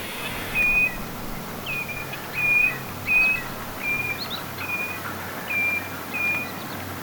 valkoviklon ääntelyä,
joukossa yksi punajalkaviklon ääni
ilm_yksi_punajalkaviklon_aani_valkoviklon_aanten_lomassa_oletettavasti_nuoria.mp3